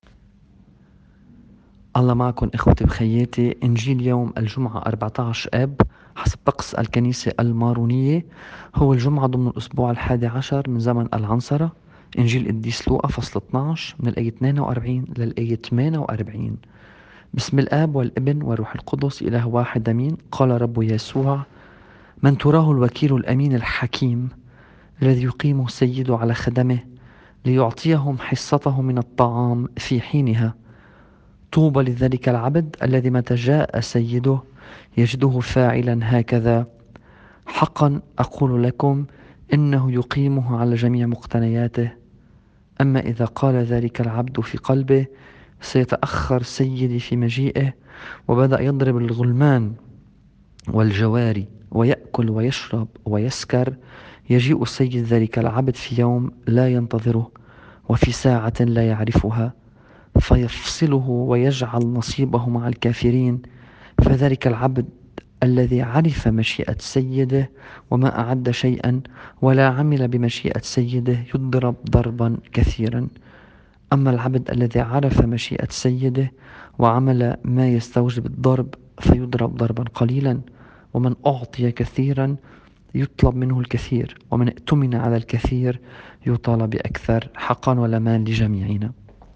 الإنجيل بحسب التقويم الماروني :